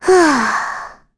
Rehartna-Vox_Sigh2_kr.wav